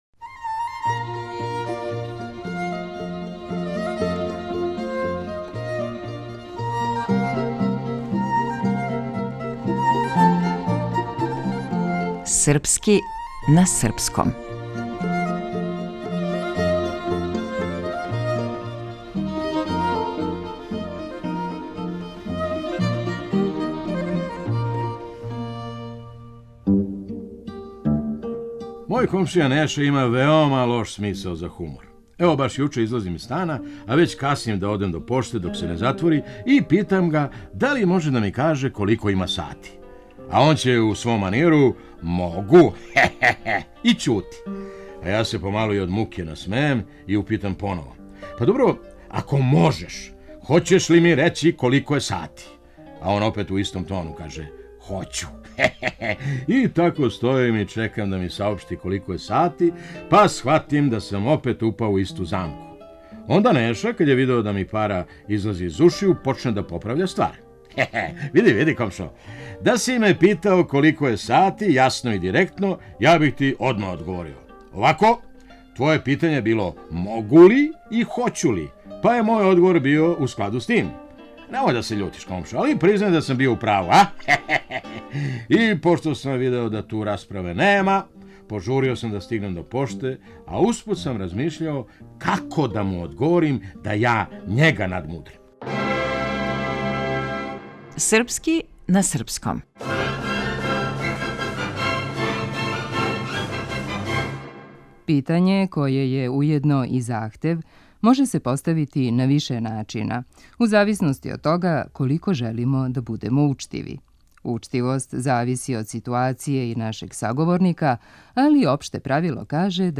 Глумац: Феђа Стојановић